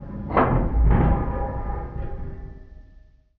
metal_low_creaking_ship_structure_03.wav